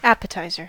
Ääntäminen
Vaihtoehtoiset kirjoitusmuodot appetiser Synonyymit starter hors d'oeuvre entrée snifter Ääntäminen US Haettu sana löytyi näillä lähdekielillä: englanti Käännös Substantiivit 1.